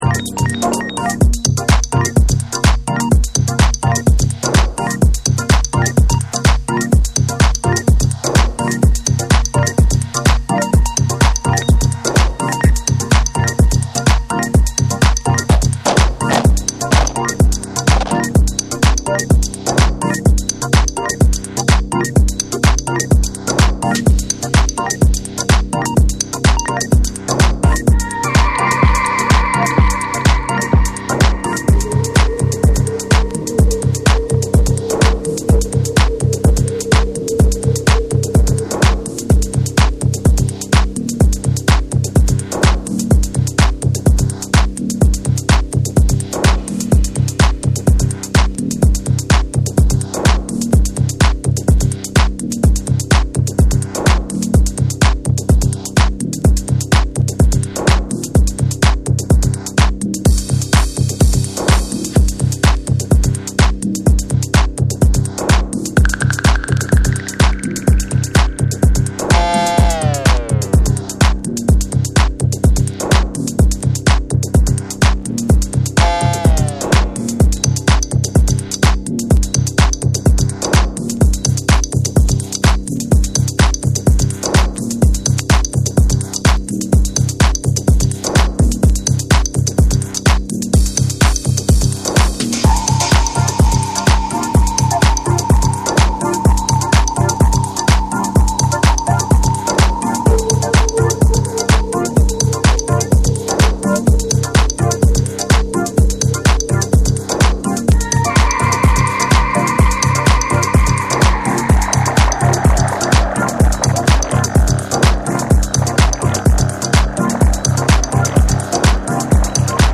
緻密でバウンシーな4/4トラックを下敷きに幻想的なメロディーが相まるテック・ハウス
TECHNO & HOUSE